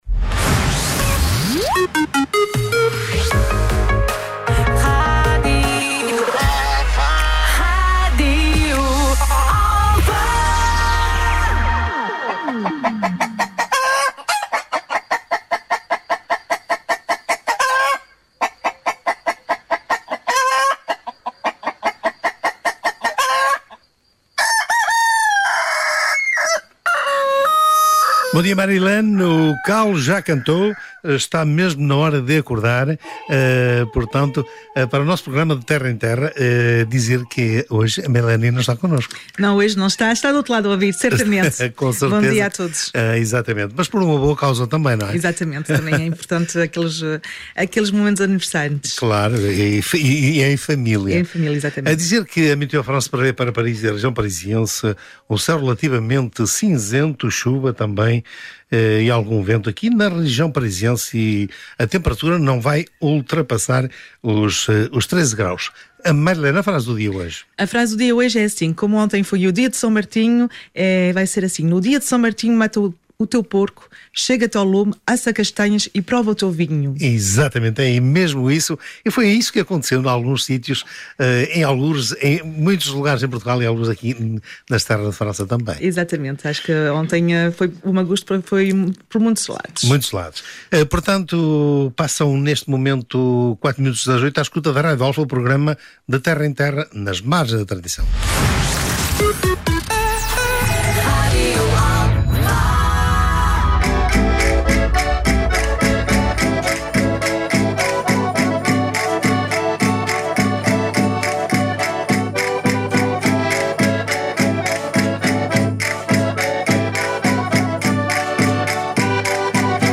A música tradicional portuguesa